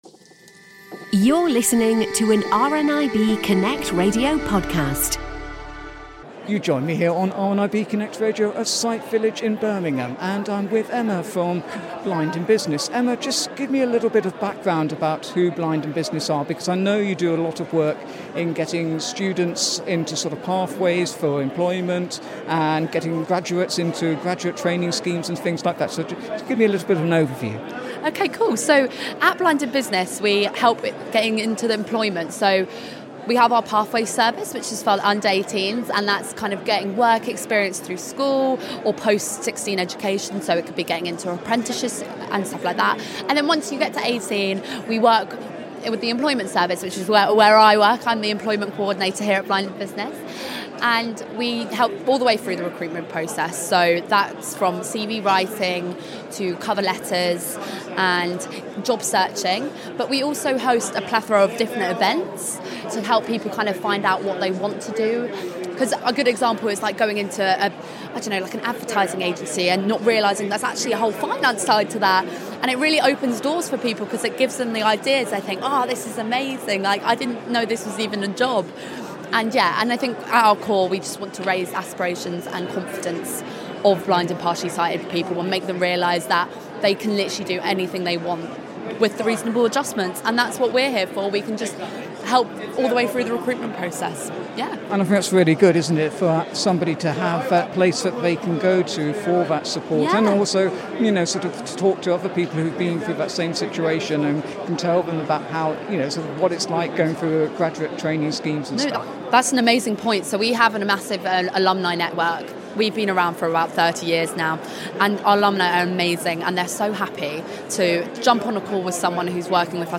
Blind in Business at Sight Village Central 2025